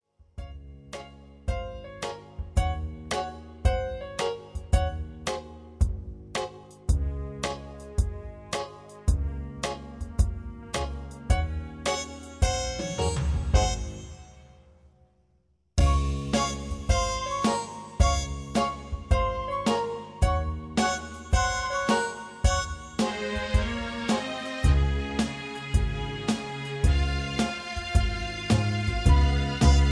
backing tracks , karaoke